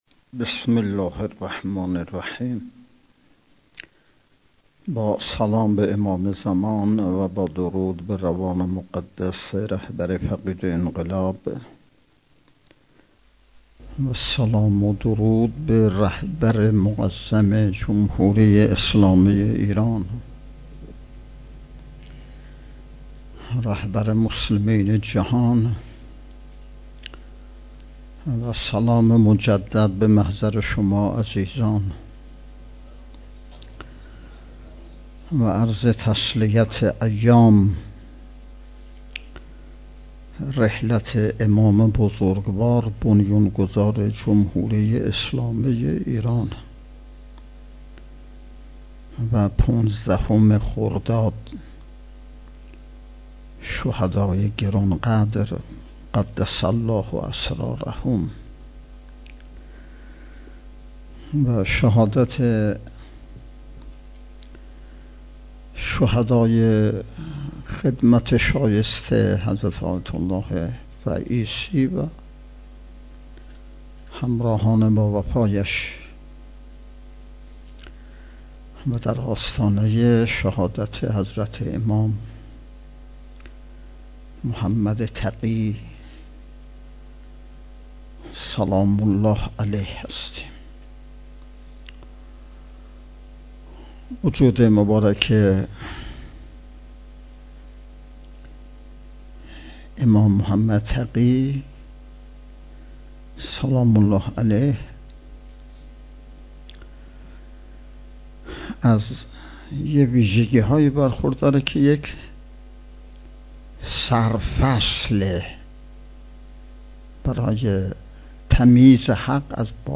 چهارمین نشست ارکان شبکه تربیتی صالحین بسیج با موضوع تربیت جوان مؤمن انقلابی پای کار، صبح امروز ( ۱۷ خرداد) با حضور و سخنرانی نماینده ولی فقیه در استان، برگزار شد.